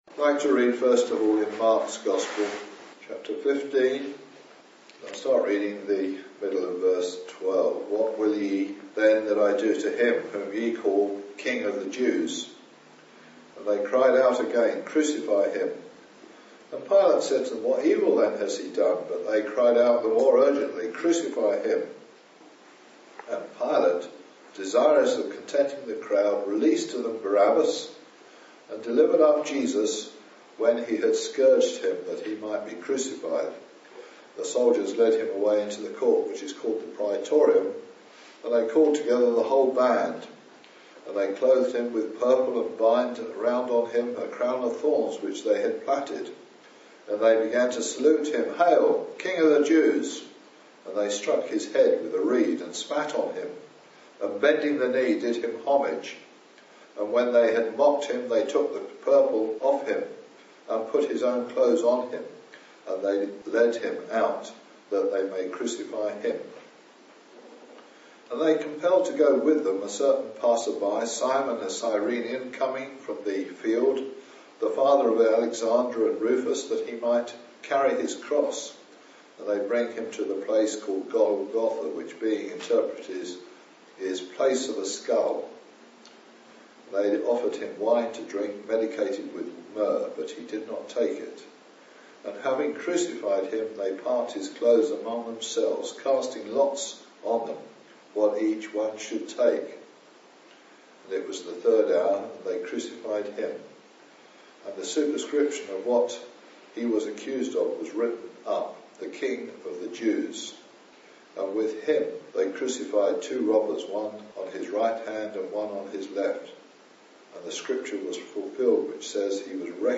As you listen to this Gospel preaching you hear of the way Salvation which can found by coming to Christ in Repentance and faith.